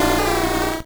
Cri d'Otaria dans Pokémon Or et Argent.